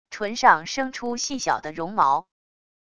唇上生出细小的绒毛wav音频生成系统WAV Audio Player